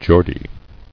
[Geor·die]